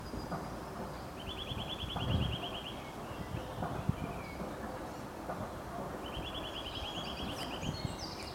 Sitta europaea
Nome em Inglês: Eurasian Nuthatch
Fase da vida: Adulto
Detalhada localização: Lockhorsterbos
Condição: Selvagem
Certeza: Gravado Vocal